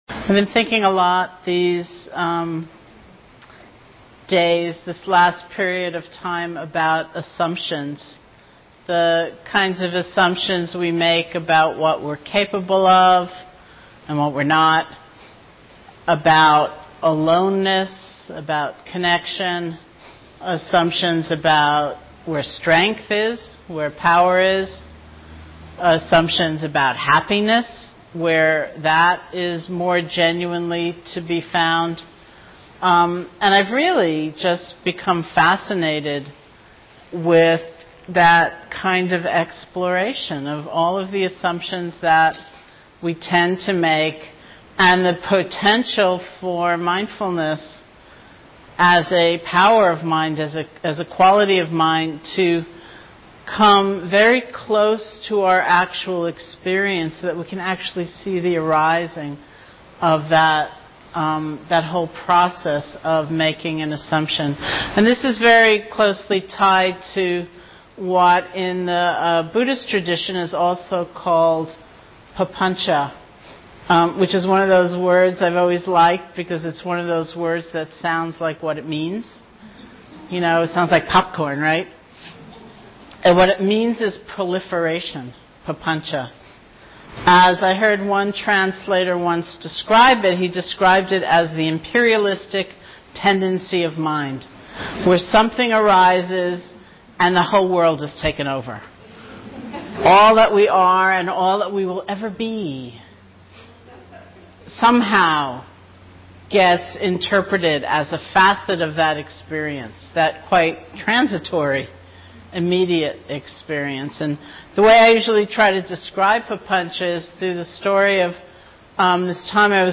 Teacher: Sharon Salzberg Date: 2009-07-26 Venue: Seattle Insight Meditation Center Series [display-posts] Description Love and space, mind and sky.